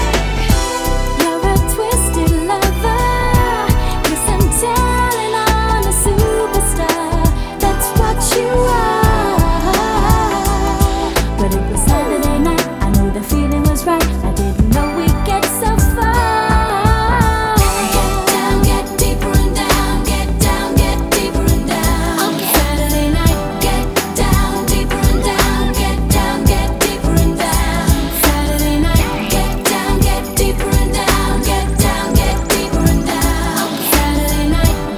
Жанр: Поп музыка / Танцевальные / Электроника